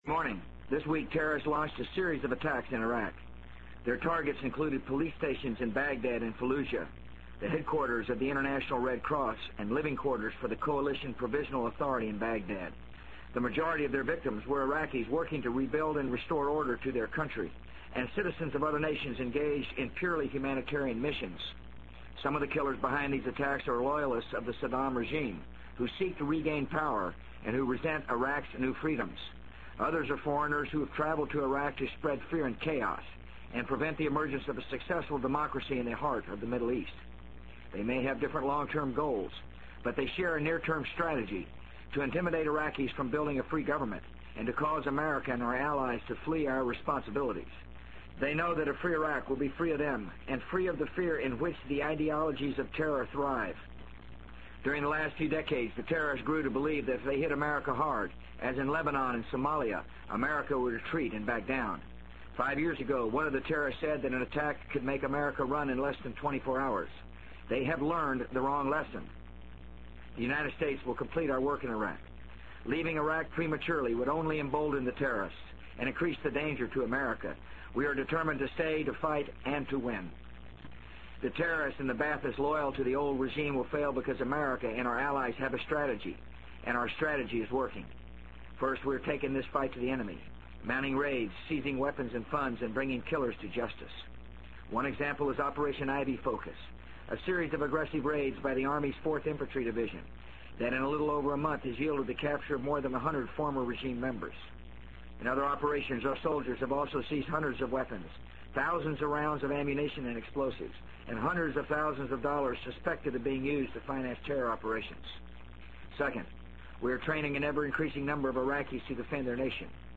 【美国总统George W. Bush电台演讲】2003-11-01 听力文件下载—在线英语听力室